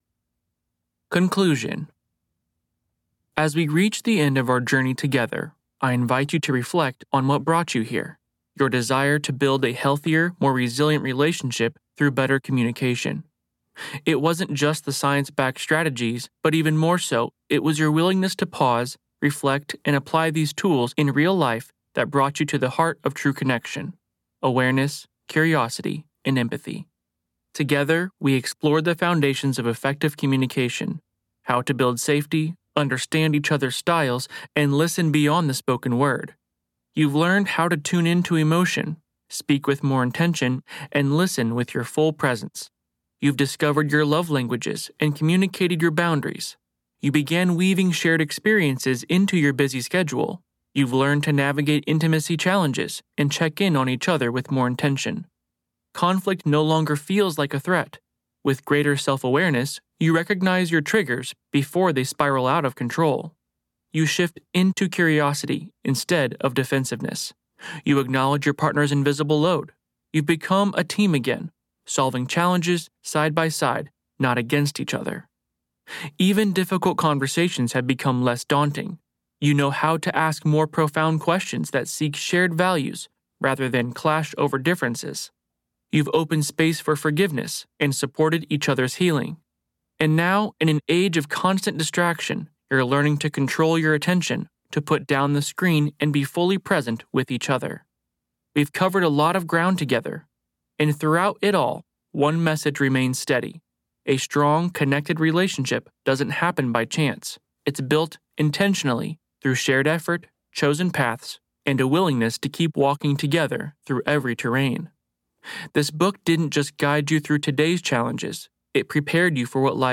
Audio Pro and Voice Artist
Audiobook